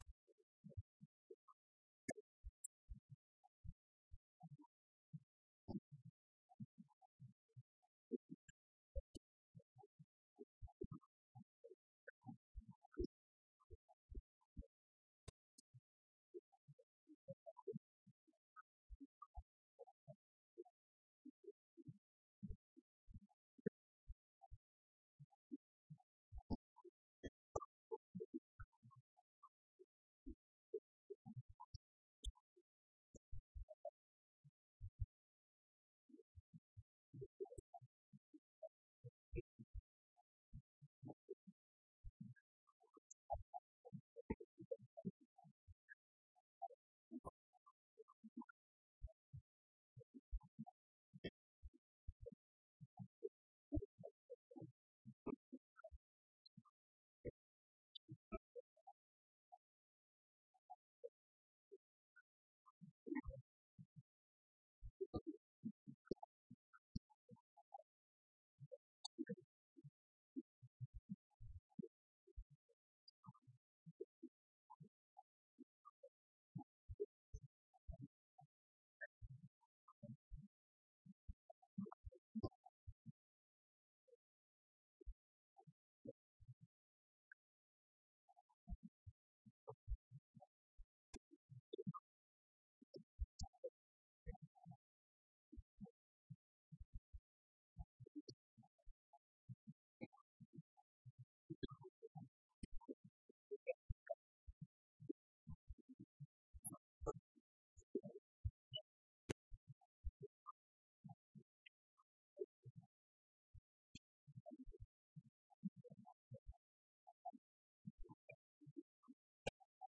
Bundesgeschäftsführer Dietmar Bartsch auf der heutigen Pressekonferenz im Berliner Karl-Liebknecht-Haus